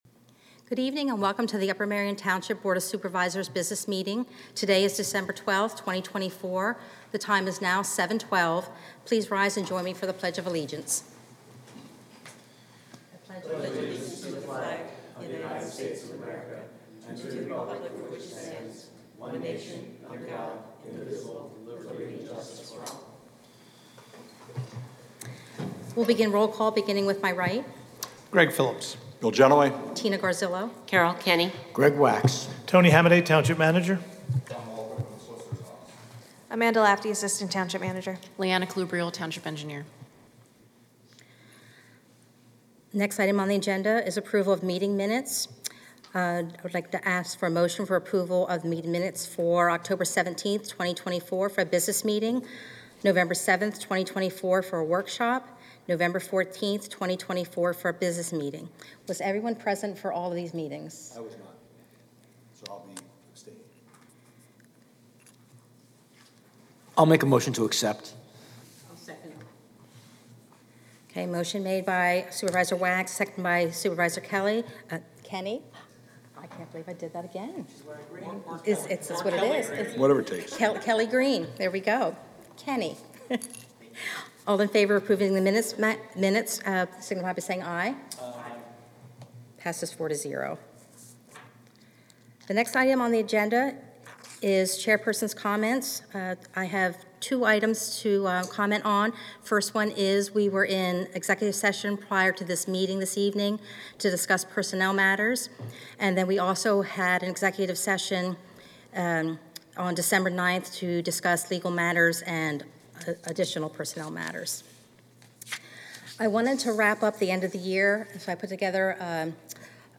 BOS Meeting - December 12, 2024